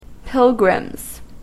/ˈpɪlgrʌmz(米国英語)/